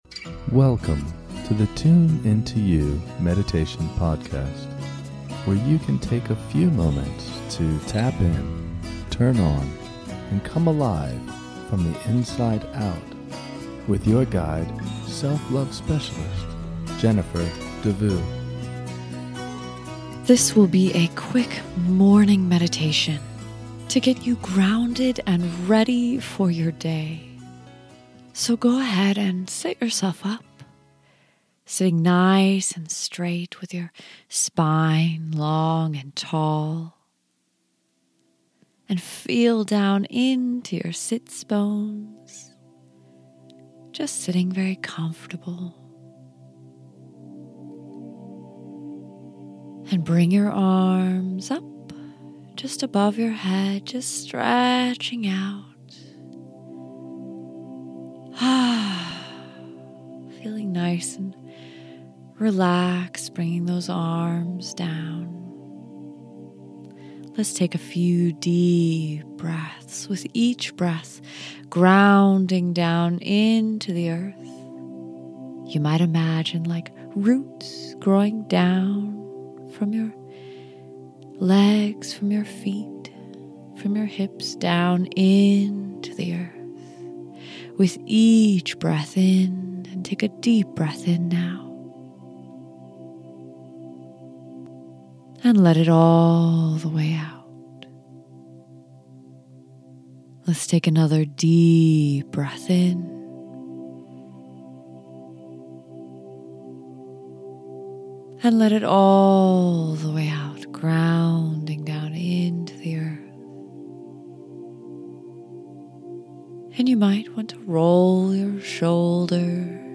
This is a short guided meditation to ground and focus first thing in the morning. Let this meditation help you set your day right with calm and relaxation.